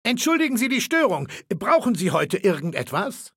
Datei:Maleold01 ms06 hello 000681b7.ogg
Fallout 3: Audiodialoge